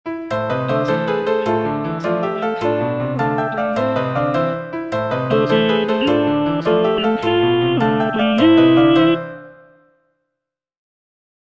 G. BIZET - CHOIRS FROM "CARMEN" Passez davant - Tenor (Voice with metronome) Ads stop: auto-stop